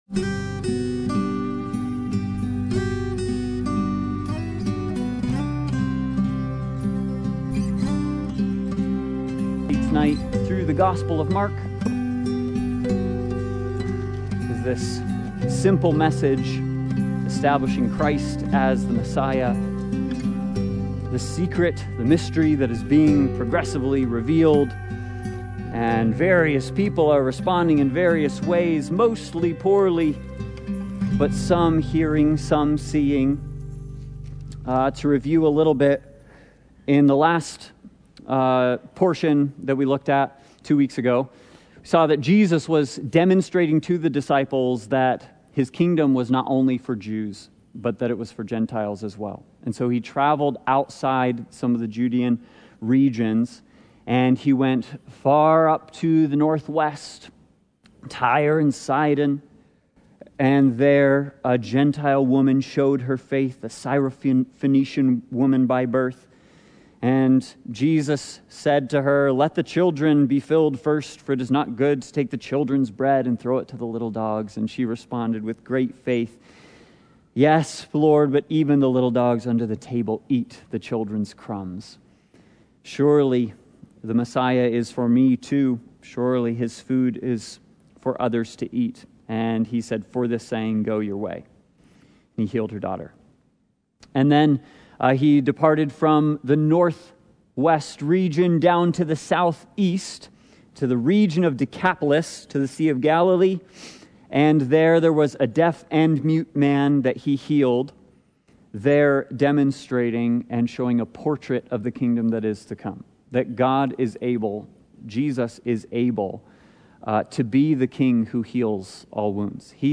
Sunday Bible Study